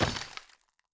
block_break.wav